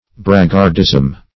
Search Result for " braggardism" : The Collaborative International Dictionary of English v.0.48: Braggardism \Brag"gard*ism\, n. [See Braggart .]